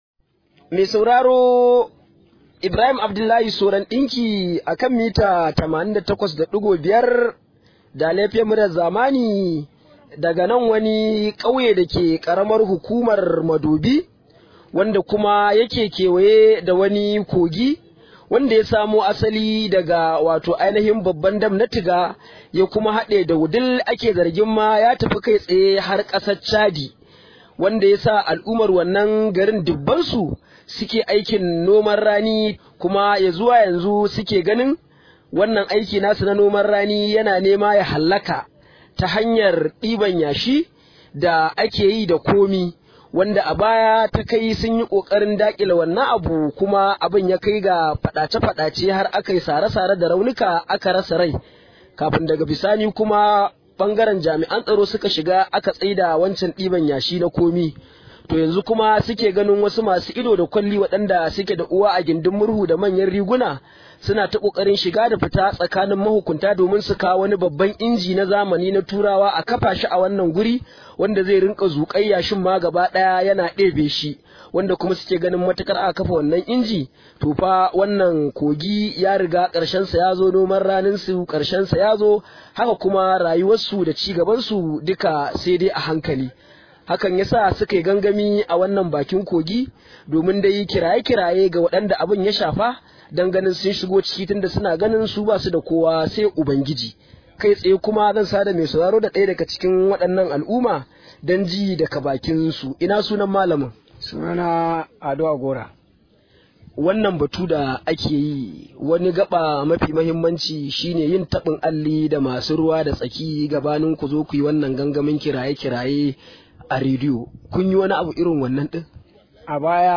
Rahoto: A kawo mana dauki a Kogin yankin mu – Kauyen Galinja